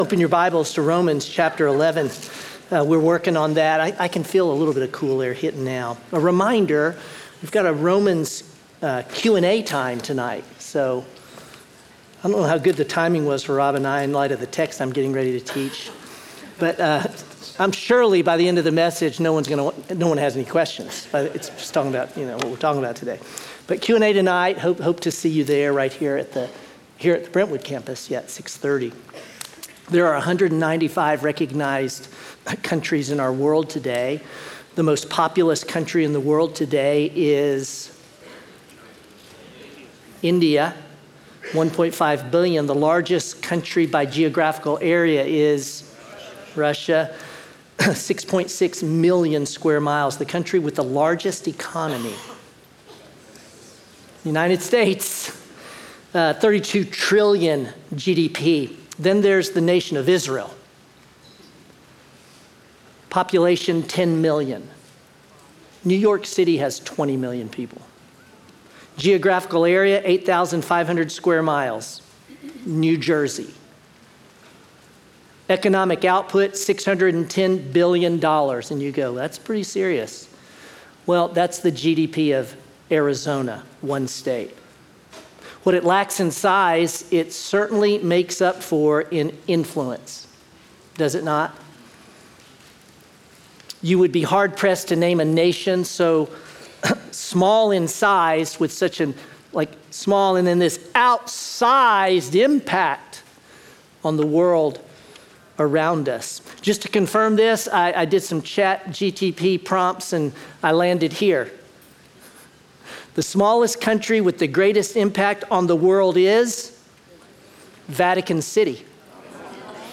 Sermon Unfathomable: Romans 9-11